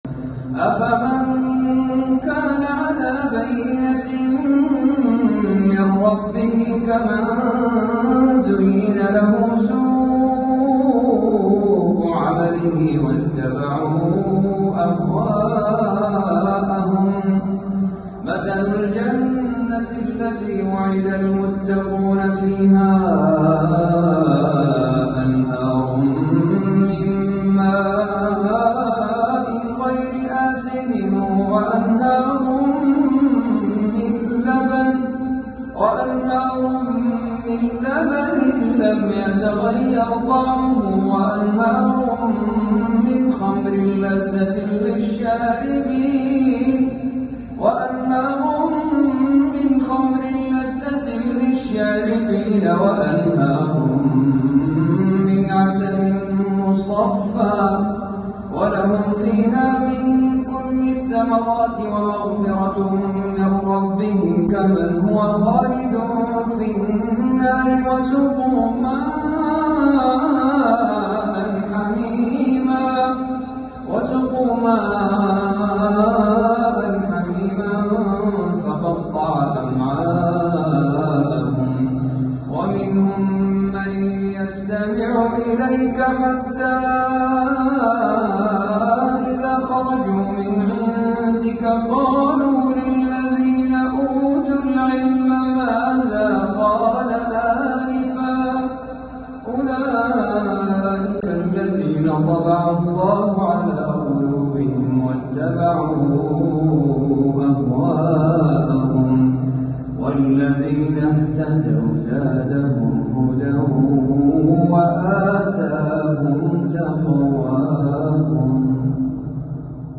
تبارك الله لقد جمع بين حسن التلاوة وحسن الصوت
تلاوة خاشعة مميزة من سورة محمد للقارئ